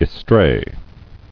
[es·tray]